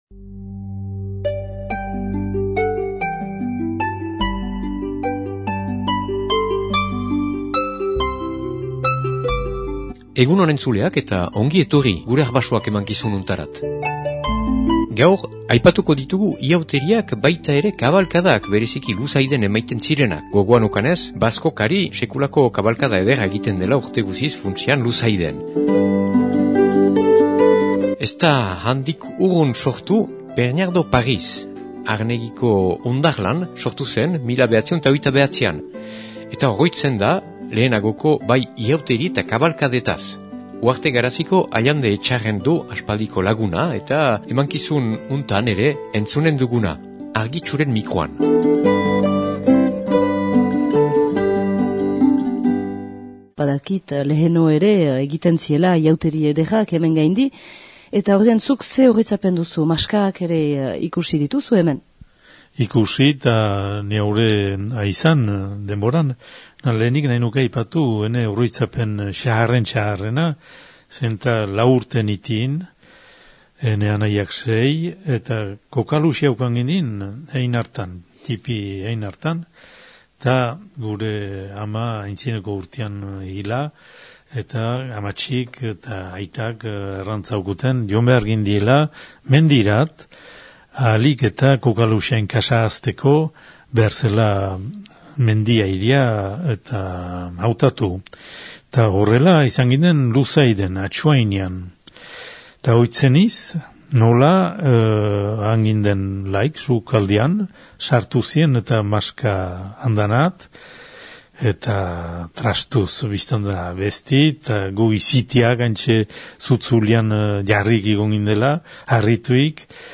Euskal Irratietan egindako elkarrizketa inauterien eta kabalkaden inguruan